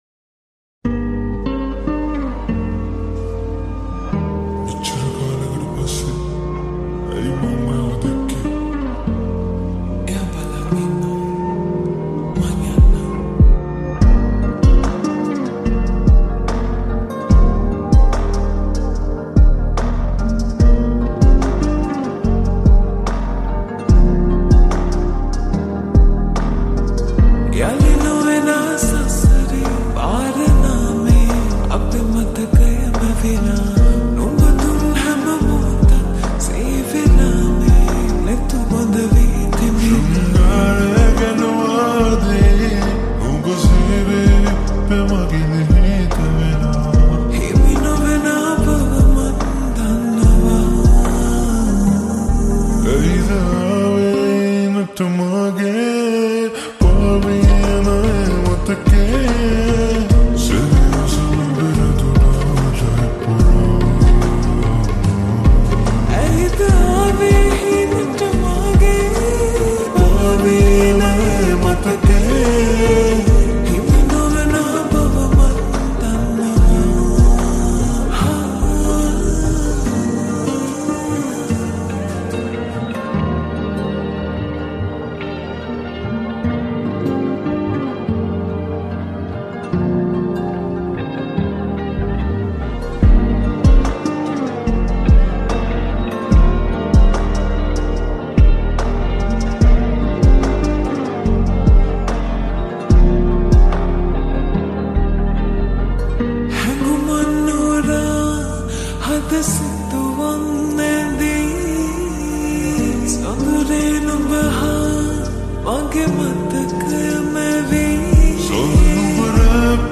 slowe reverb